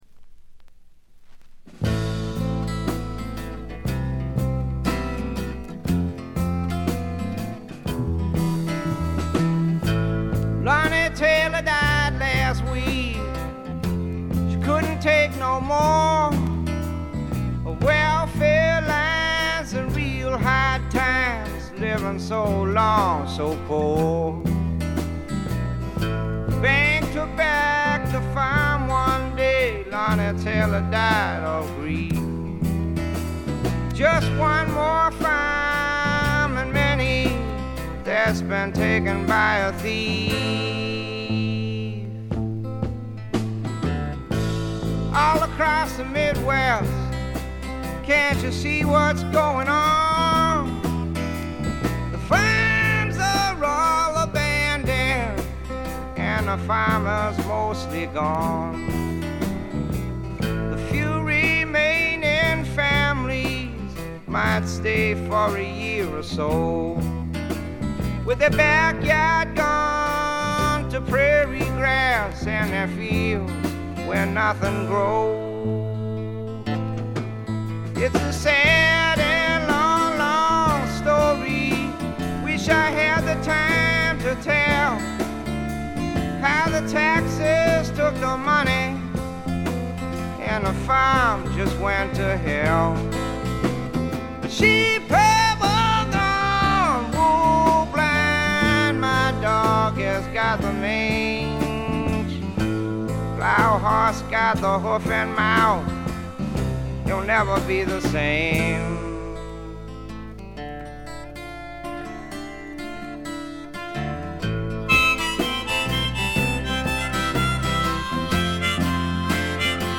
ごくわずかなチリプチ程度。
試聴曲は現品からの取り込み音源です。
Vocals, Guitar, Harmonica
Dobro, Guitar
Keyboards
Bass
Drums